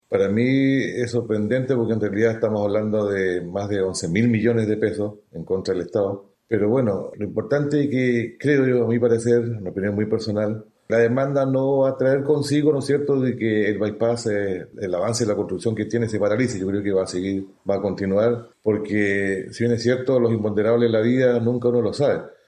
Al respecto, el alcalde de Castro, Baltazar Elgueta, descartó que este requerimiento afecte al avance de la obra.